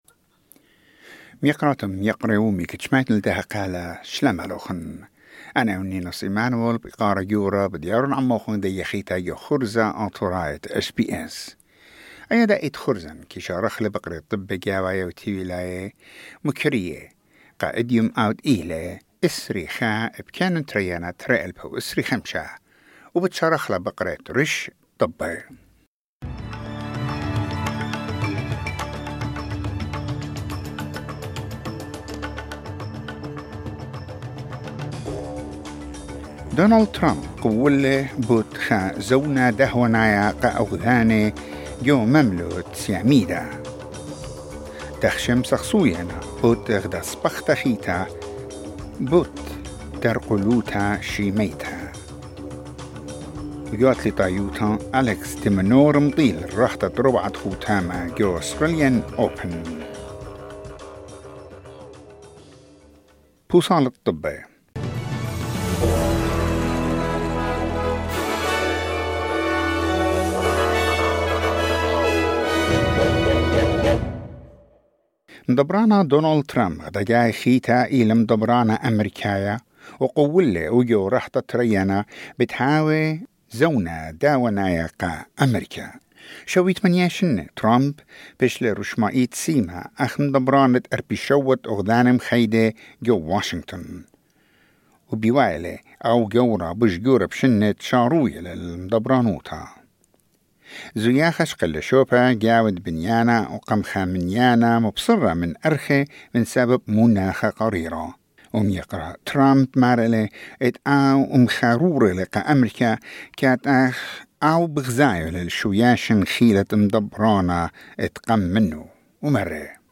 SBS Assyrian news bulletin: 21 January 2025